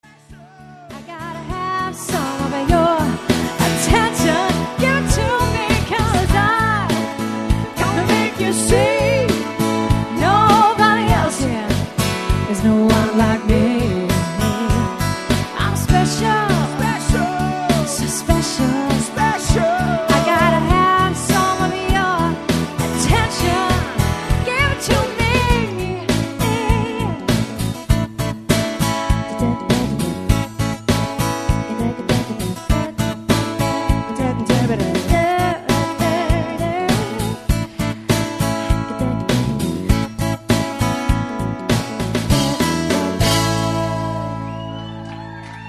Guitar Vocals - Female Vocals - Keyboard - Bass - Drums - 2nd Female Vocal
delivering an energetic, fresh and interactive performance.